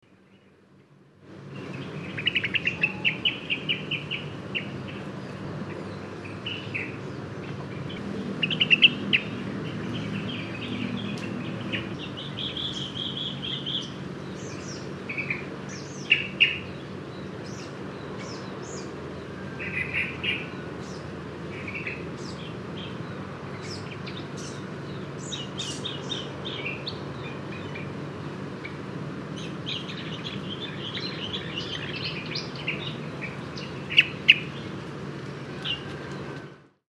(CHESTNUT-HEADED BEE-EATER)